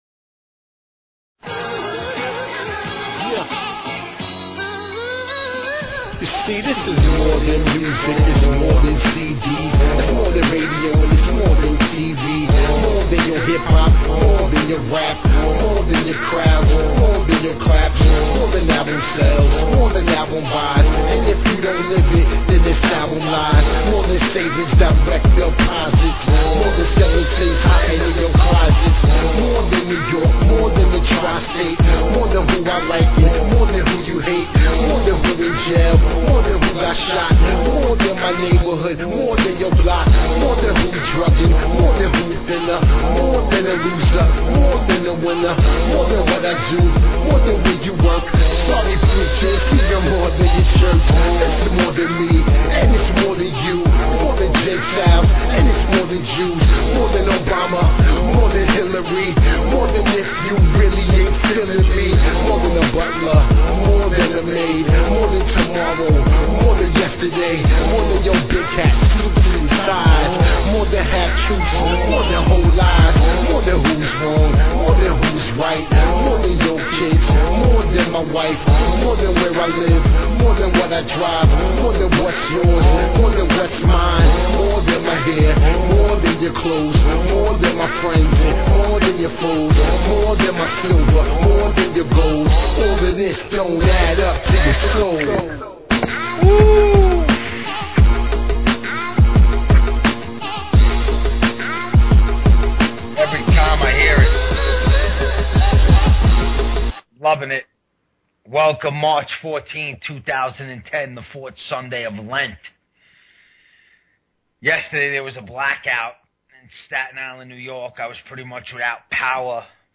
Talk Show Episode, Audio Podcast, Soldiers_of_Christ and Courtesy of BBS Radio on , show guests , about , categorized as
PREACHIN THE WORD WITH CHRISTIAN HIP HOP AND SOUL!
Spreading the good word; plus hip hop and soul inspired by the Gospel!